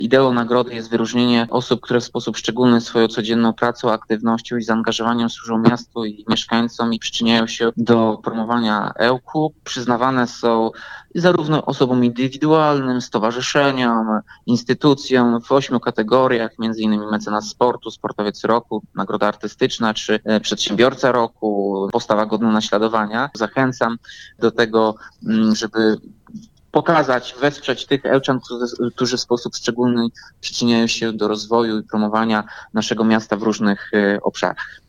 Mówił Tomasz Andrukiewicz.